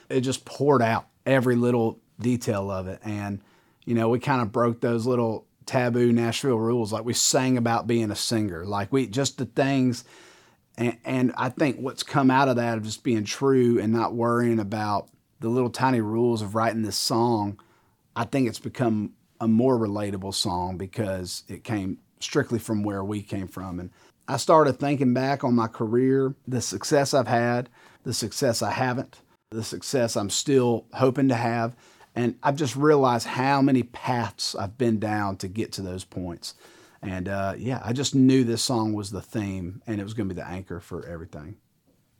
Travis Denning talks about the inspiration behind the title track of his forthcoming album, Roads That Go Nowhere.